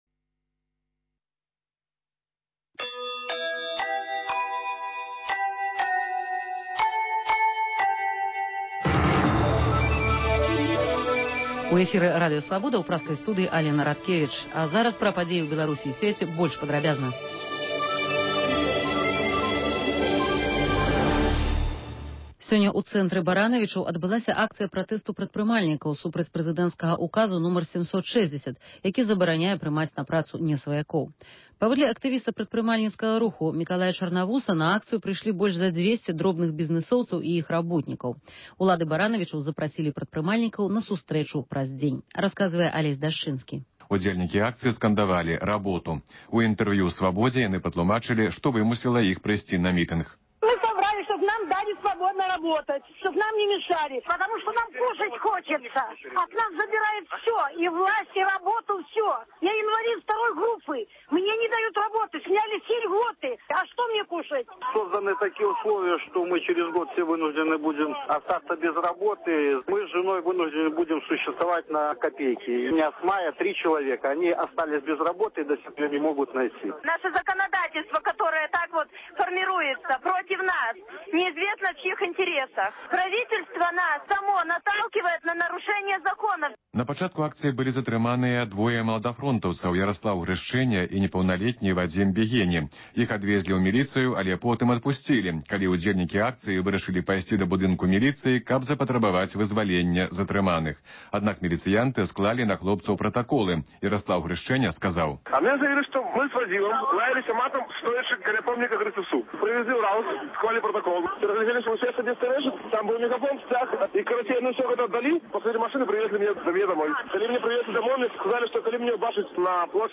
Паведамленьні карэспандэнтаў "Свабоды", званкі слухачоў, апытаньні на вуліцах беларускіх гарадоў і мястэчак.